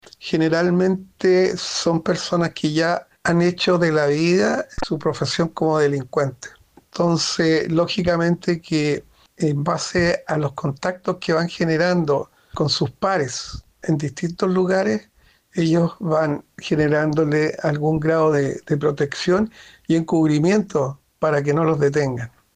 cu-detenido-san-antonio-experto.mp3